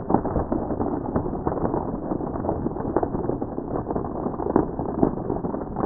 ICE_Cracking_Deep_loop_mono.wav